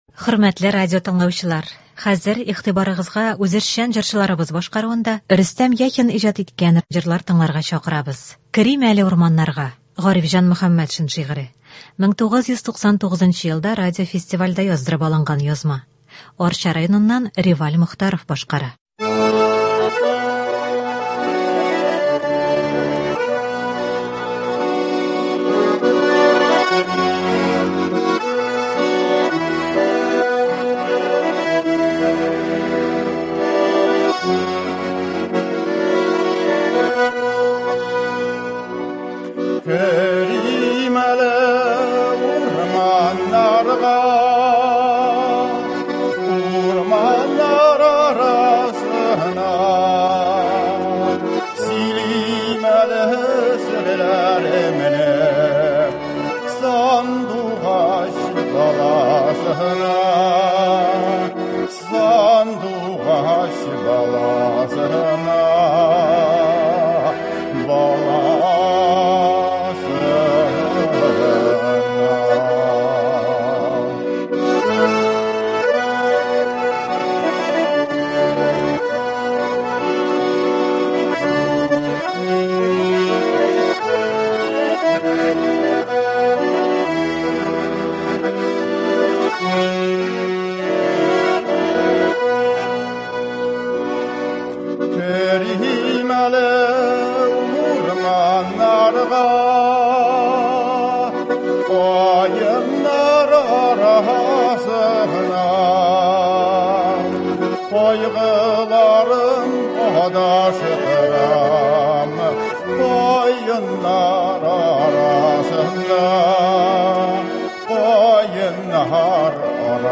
Кичке концерт.